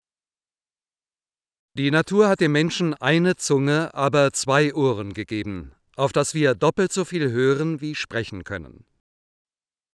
male.wav